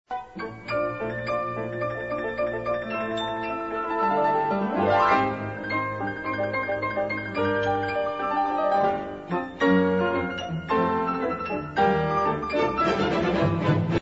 حالت کلی این موومان، فعالیتی بی امان و پر شور را مجسم می دارد.
تم اول گاهی لحن مارش مانندی پیدا می کند.